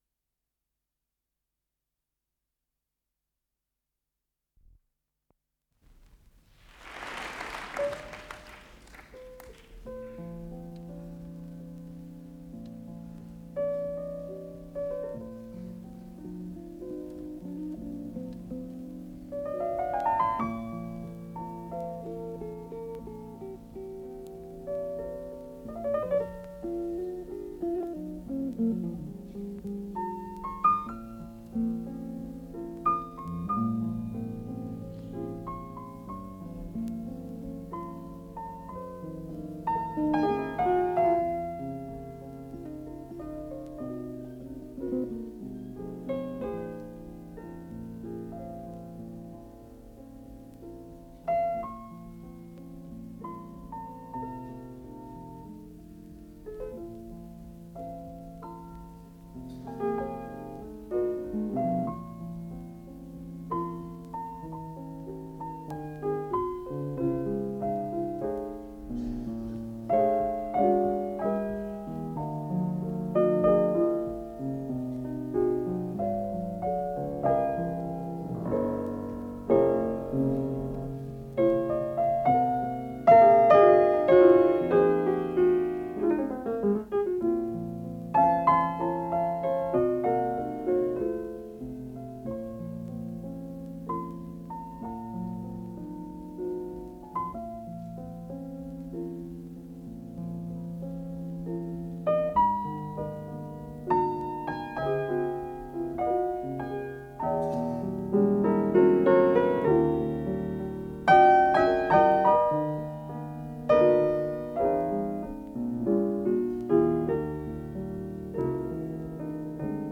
с профессиональной магнитной ленты
ВариантДубль стерео